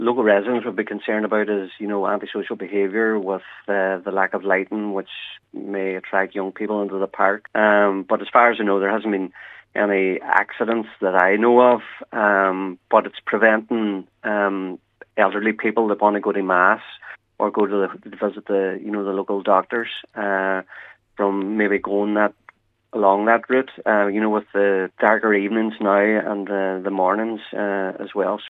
Cllr Harte says that there is no lighting on the walkways with anti-social behaviour and access to the town continuing to be a concern for residents: